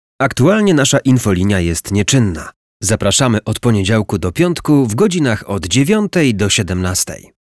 Przykład nagrania lektorskiego zapowiedzi telefonicznej poza godzinami pracy firmy:
ZapowiedziTelefoniczne_pozagodzinami_2029.mp3